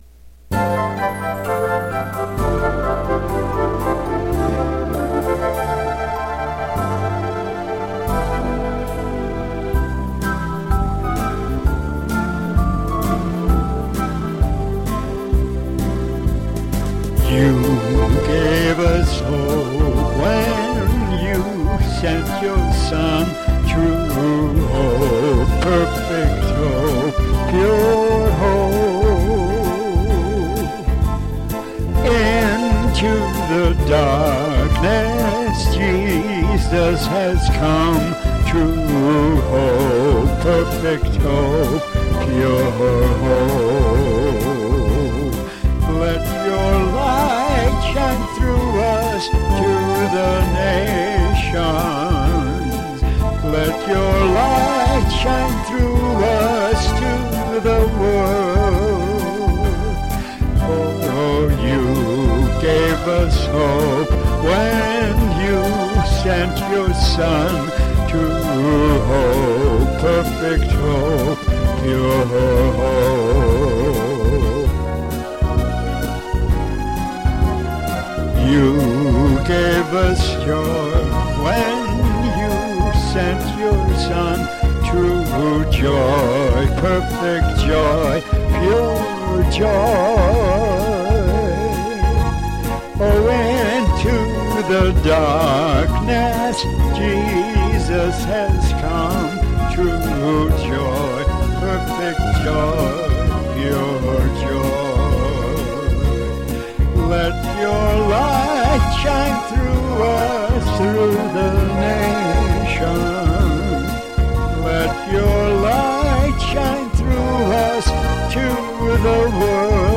Karaoke Video